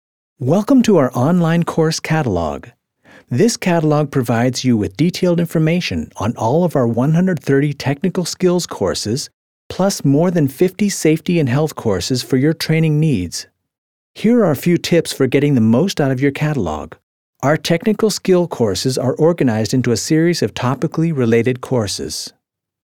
US-Amerikaner, wohnhaft in Deutschland, English Native Speaker, Image-Filme, Erklär-Videos, spreche auch Deutsh
mid-atlantic
Sprechprobe: eLearning (Muttersprache):